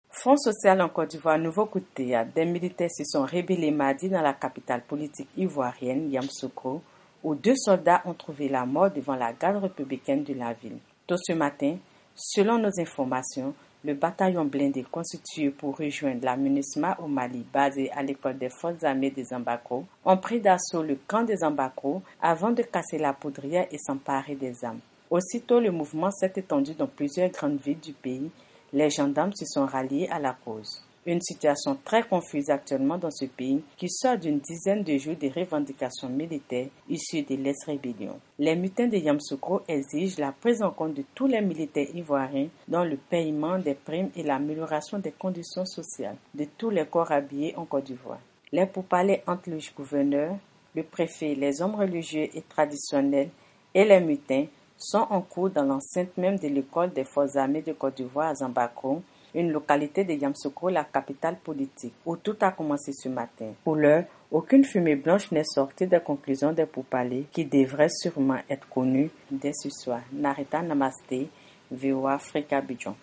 Récit